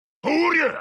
ganondorf-voices-super-smash-bros-ultimate-mp3cut.mp3